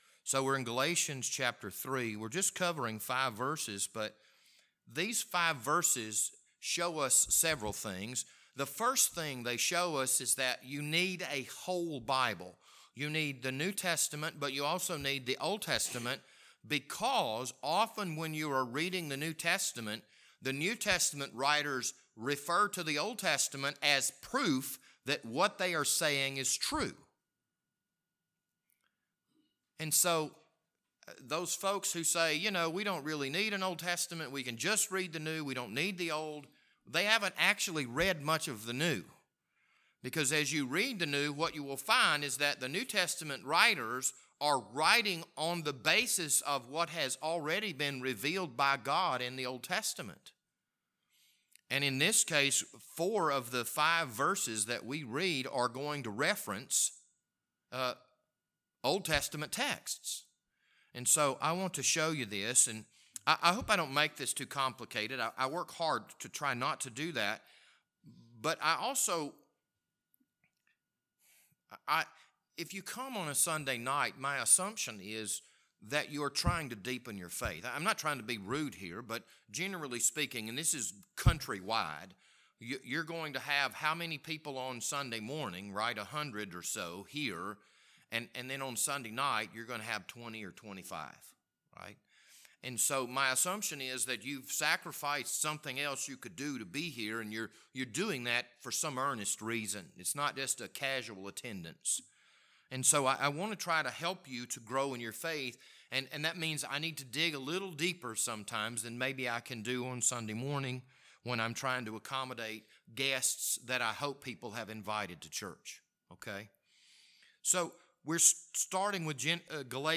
This Sunday evening sermon was recorded on April 24th, 2022.